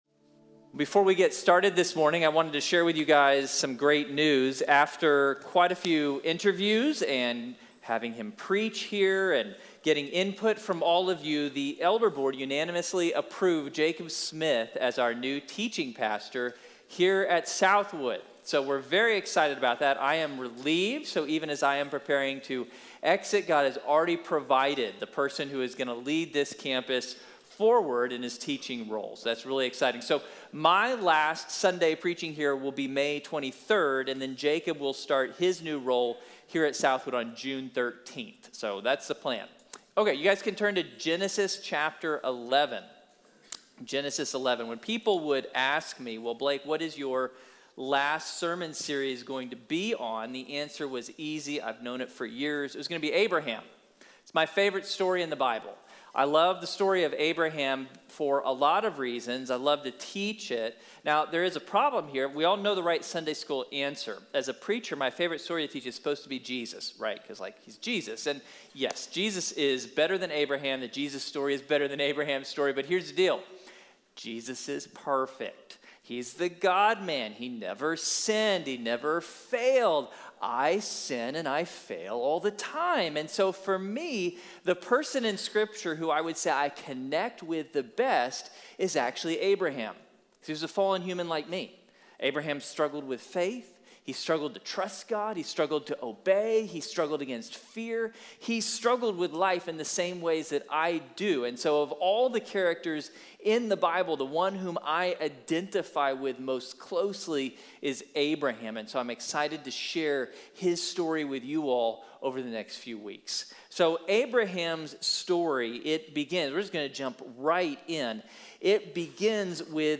Abraham’s Promise | Sermon | Grace Bible Church